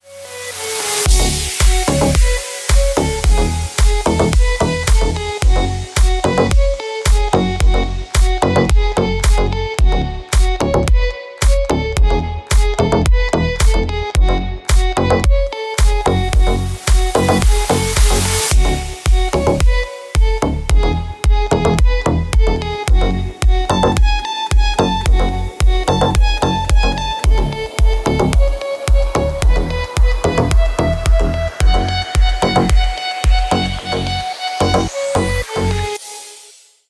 Электроника
клубные # громкие # без слов